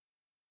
whoosh.ogg